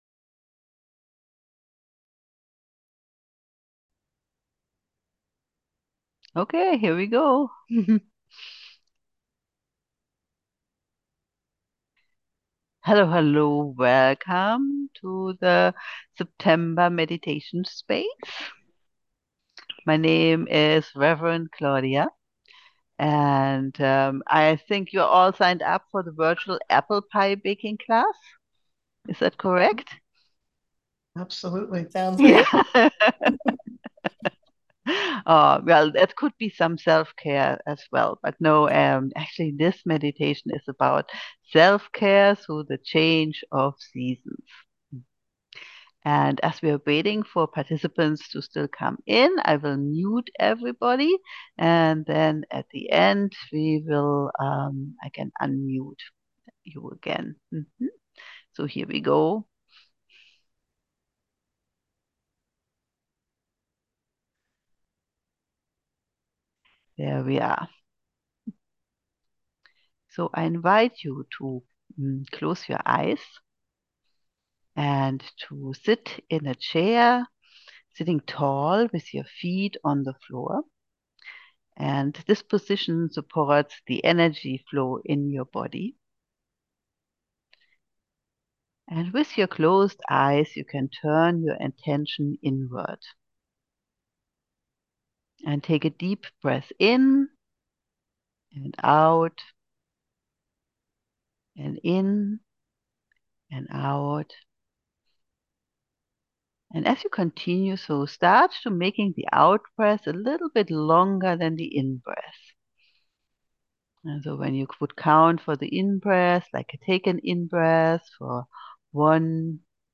28 minutes Recorded Live on September 11